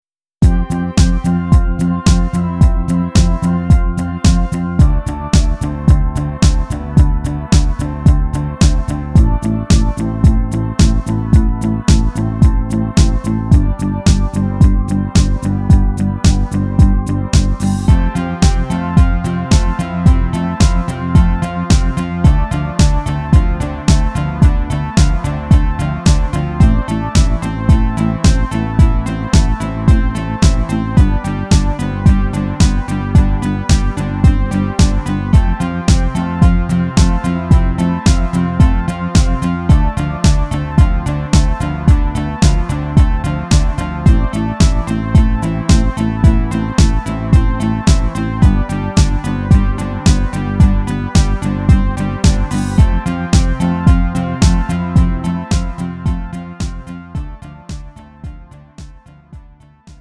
음정 -1키 5:39
장르 가요 구분 Lite MR